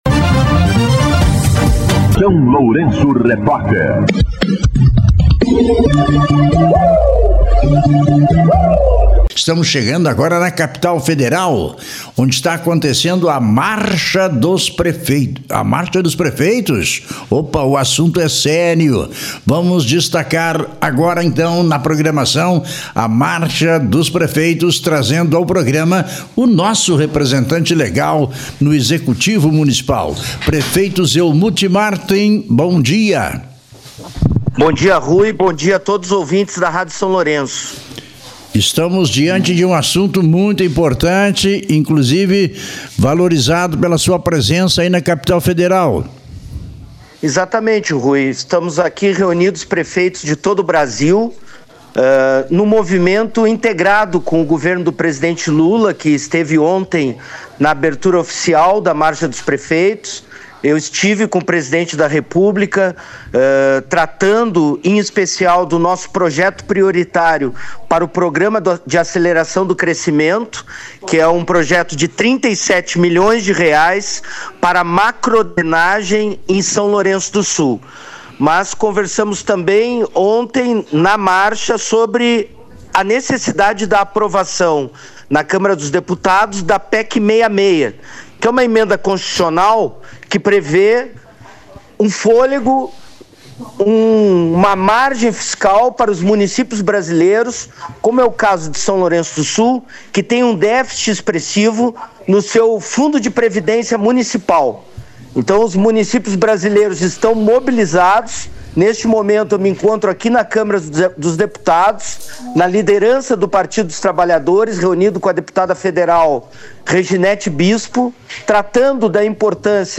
De lá, o prefeito Zelmute Marten (PT), falou com a reportagem do SLR na manhã desta quarta-feira (21), tendo o Programa de Aceleração do Crescimento (PAC) como prioridade, segundo mencionou.
Entrevista com o prefeito Zelmute Marten